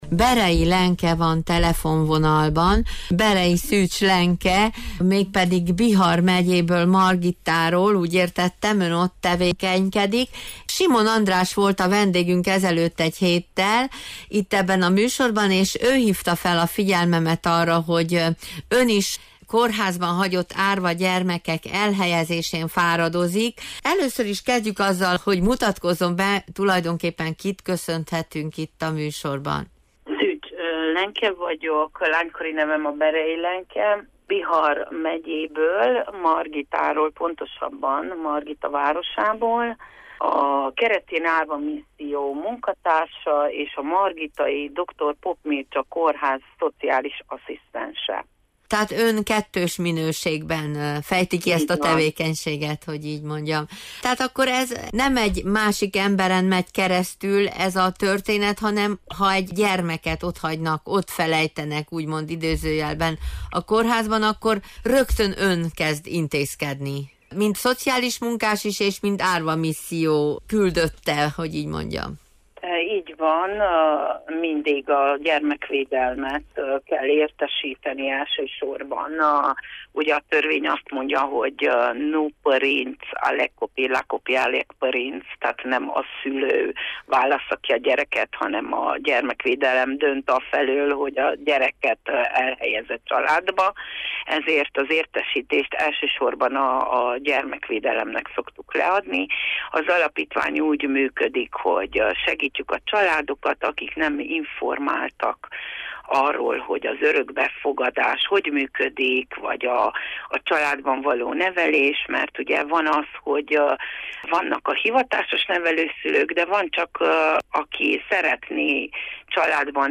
akivel telefonon beszélgettünk idén szeptember 28-án, a Lachobacht műsorában.